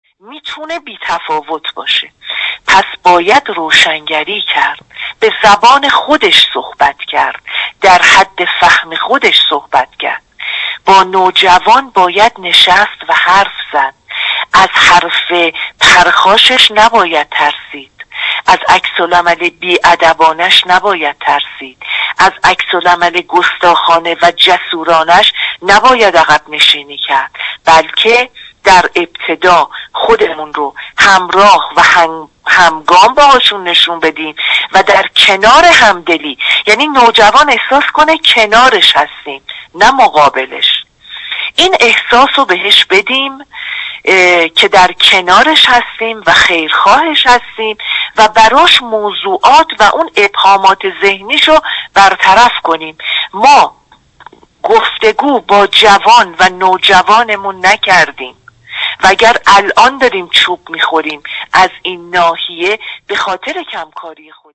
ایکنا - سخن پایانی.